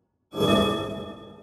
Trimmed-Holy Buffs